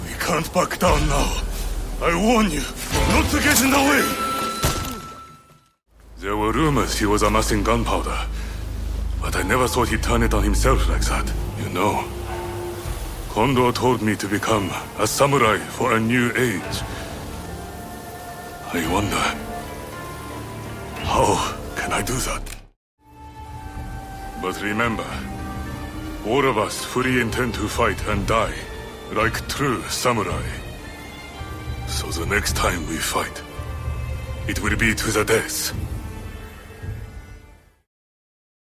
Voice and facial capture was provided by PitStop Productions.